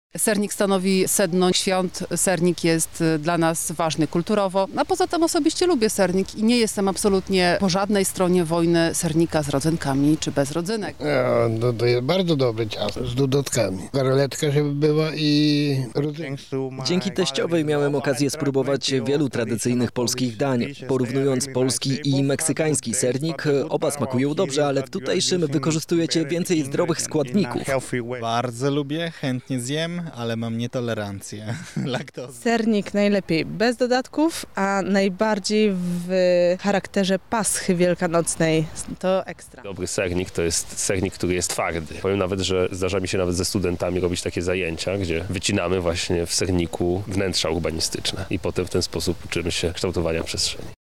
W jakiej formie jedzą go lublinianie, o to zapytaliśmy mieszkańców naszego miasta:
sonda